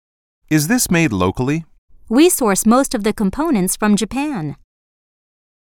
實境對話